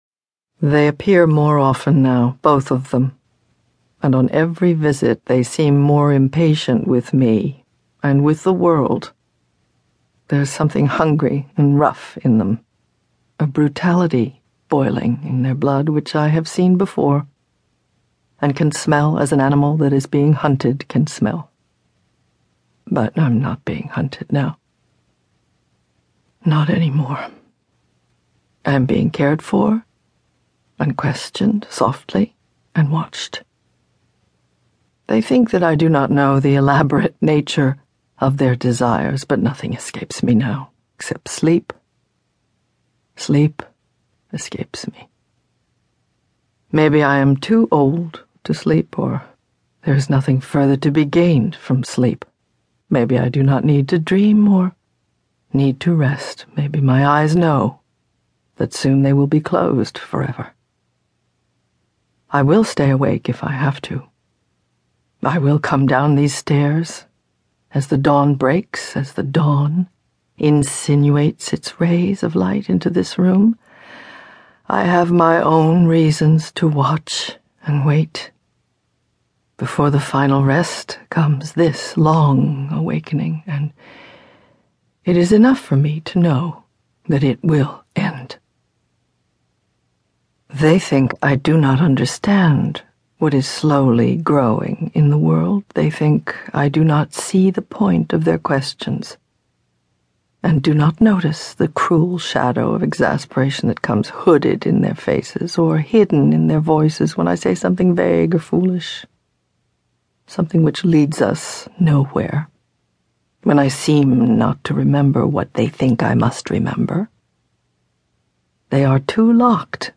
Provocative, haunting, and indelible, Meryl Streep's performance of Colm Tóibín's acclaimed The Testament of Mary presents Mary as a solitary older woman still seeking to understand the events that become the narrative of the New Testament and the foundation of Christianity. In the ancient town of Ephesus, Mary lives alone, years after her son's crucifixion.